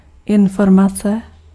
Ääntäminen
Synonyymit nouvelle nouvelles renseignement donnée Ääntäminen France: IPA: [ɛ̃.fɔʁ.ma.sjɔ̃] Haettu sana löytyi näillä lähdekielillä: ranska Käännös Ääninäyte Substantiivit 1. informace {f} 2. údaj {m} 3. echo Suku: f .